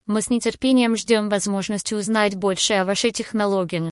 AI Voice in Russian
Russian-TTS.mp3